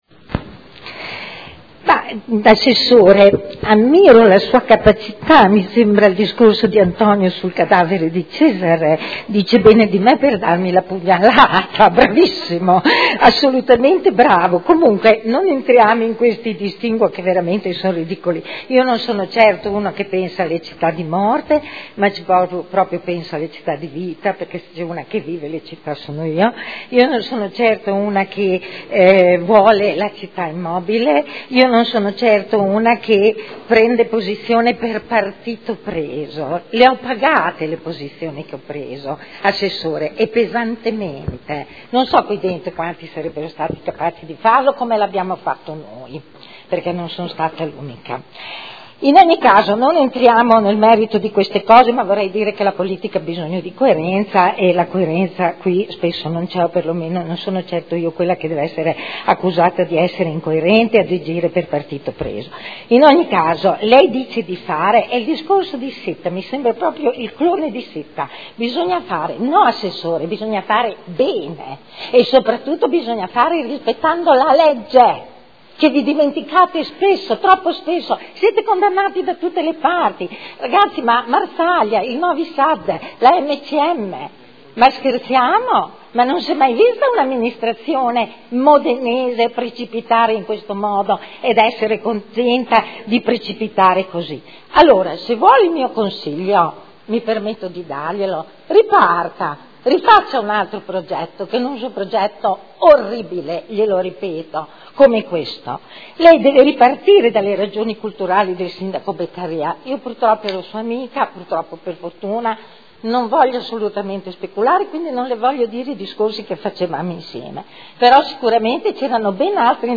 Eugenia Rossi — Sito Audio Consiglio Comunale